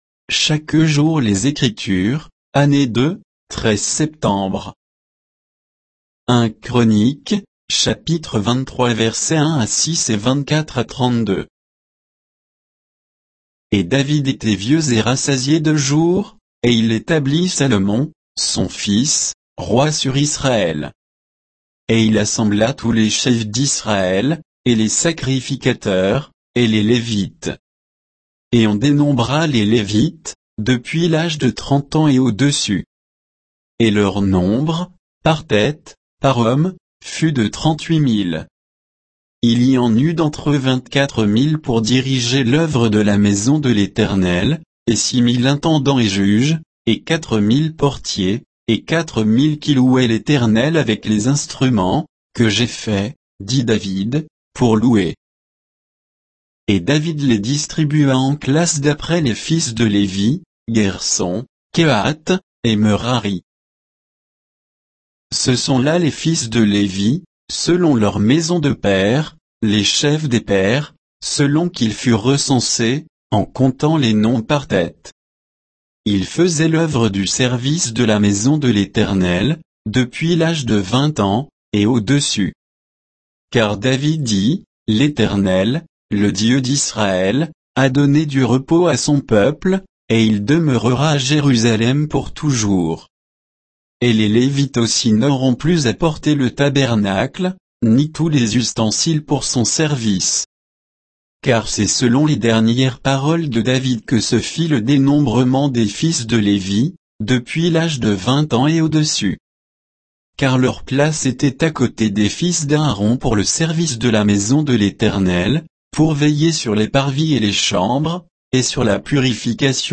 Méditation quoditienne de Chaque jour les Écritures sur 1 Chroniques 23